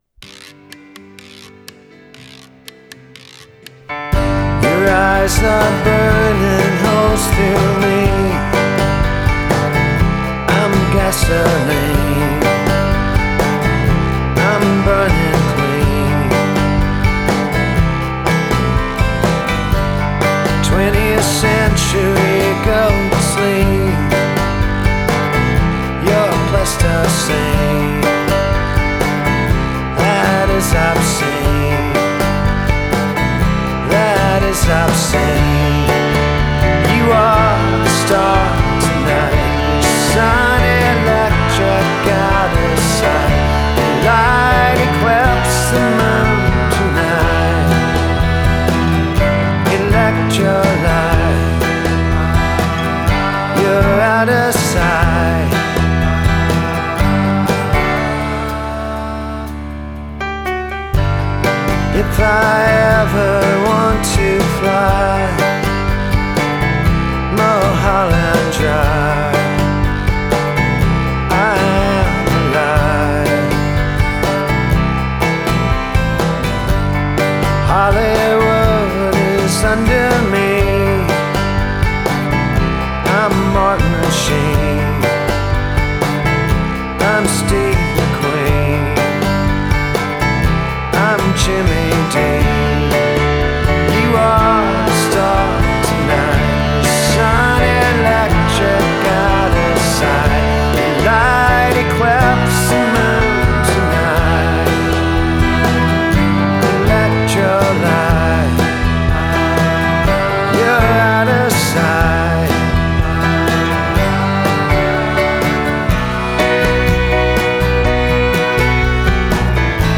piano-led track